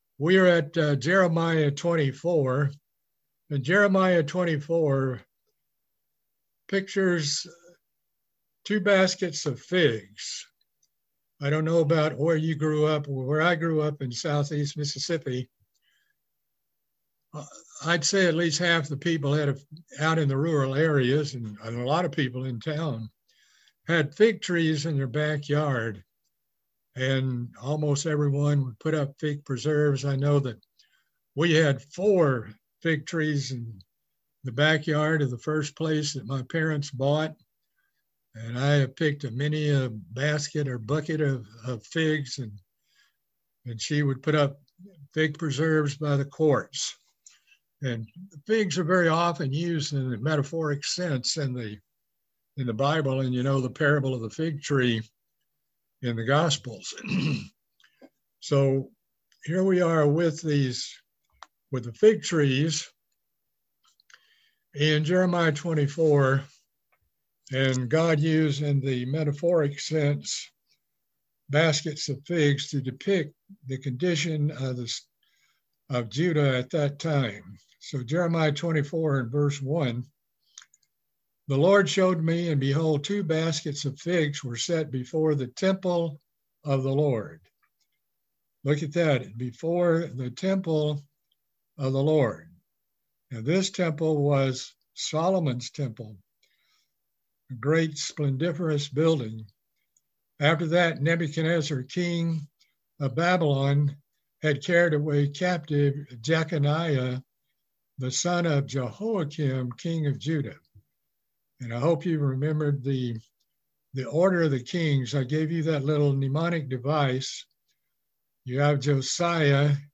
Bible study series on the book of Jeremiah - Part 15